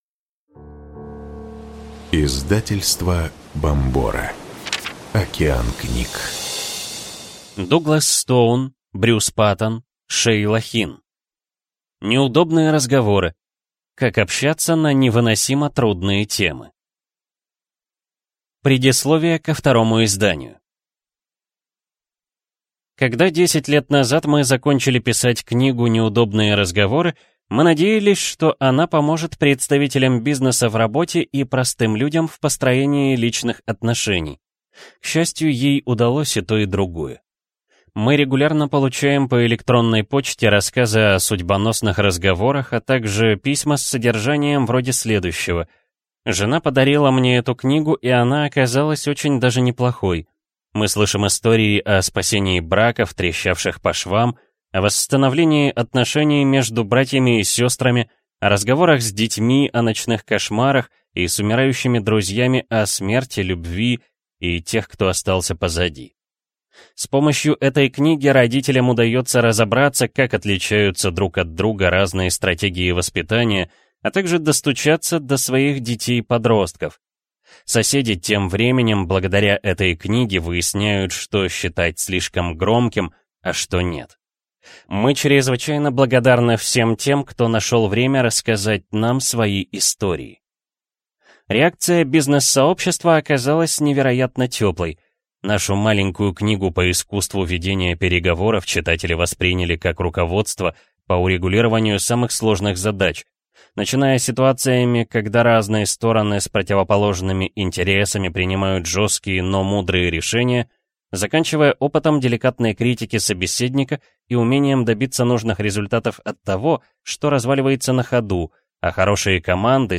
Аудиокнига Неудобные разговоры. Как общаться на невыносимо трудные темы | Библиотека аудиокниг